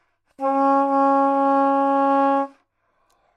萨克斯高音单音（吹得不好） " 萨克斯高音 C4 丰富性不好 肢体不健全
描述：在巴塞罗那Universitat Pompeu Fabra音乐技术集团的goodsounds.org项目的背景下录制。单音乐器声音的Goodsound数据集。
标签： 睦邻听起来 萨克斯 单注 多样本 女高音 纽曼-U87 Csharp4
声道立体声